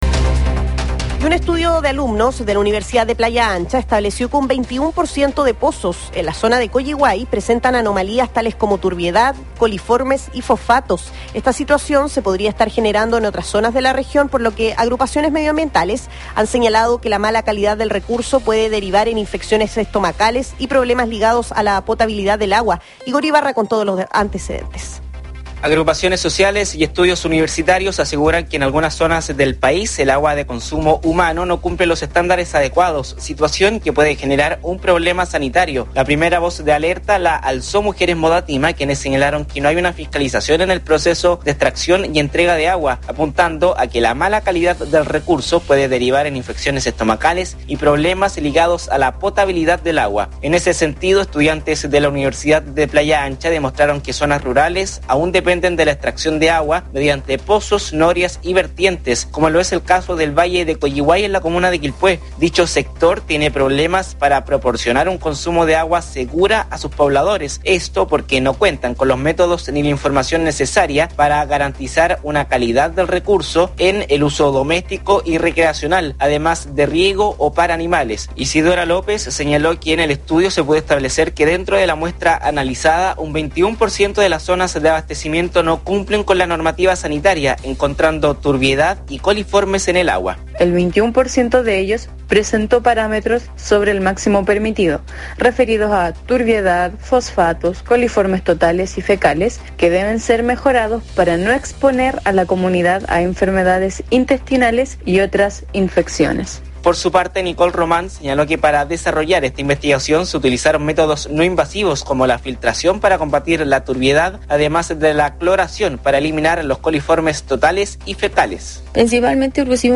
La información radial reproduce una nota publicada previamente en Noticias UPLA, que contiene algunos antecedentes adicionales sobre el trabajo en Colliguay de las jóvenes investigadoras de la UPLA.